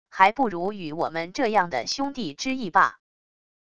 还不如与我们这样的兄弟之义罢wav音频生成系统WAV Audio Player